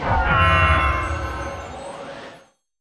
Cri de Glaivodo dans Pokémon Écarlate et Violet.